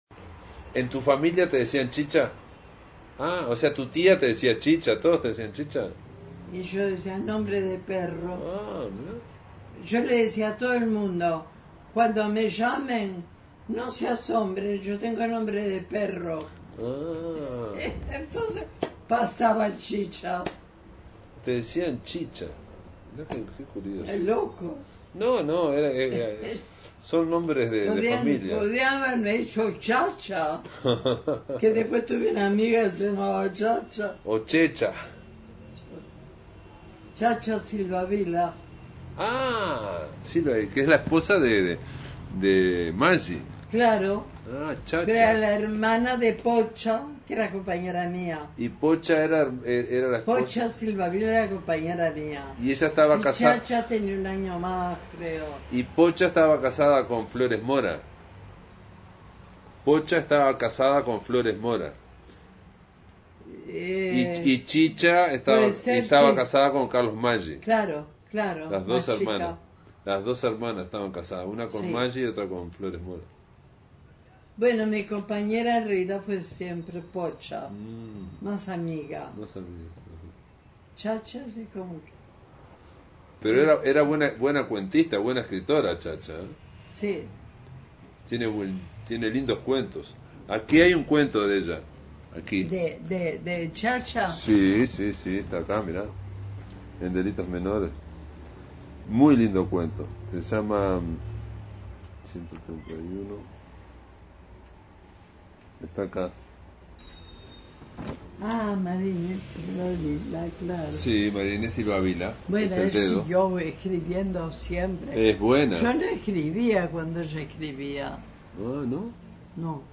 Compartimos una entrevista con Ida Vitale, realizada en su casa el 11 de abril de 2025
Ida Vitale lee sus textos